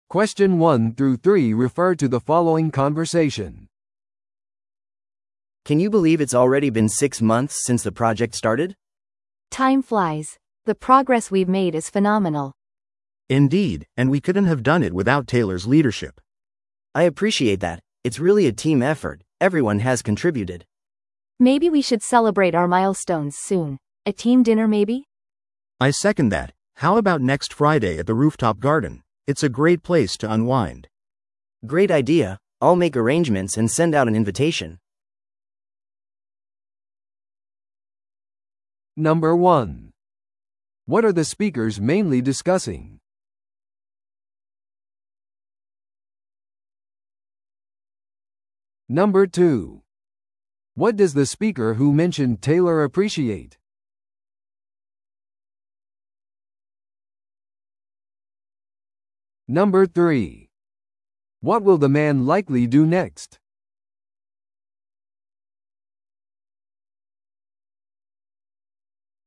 TOEICⓇ対策 Part 3｜プロジェクト達成のチームディナーについて – 音声付き No.173